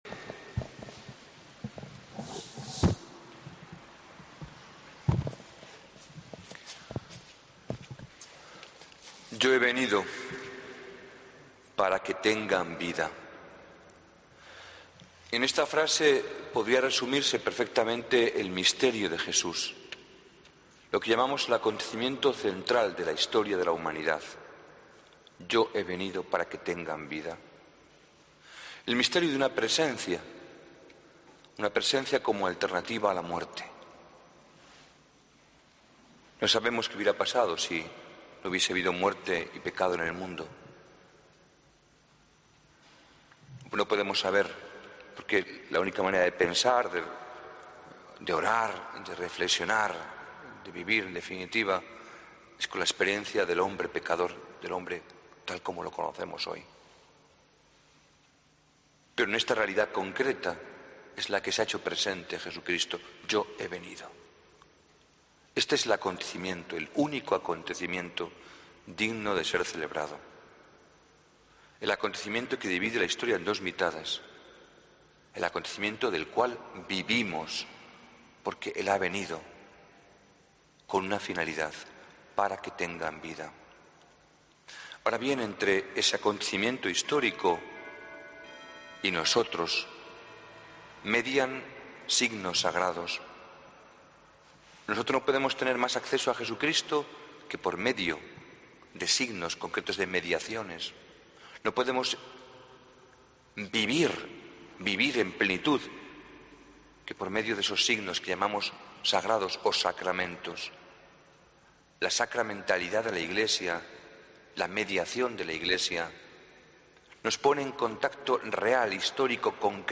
Homilía del Domingo 6 de Abril de 2014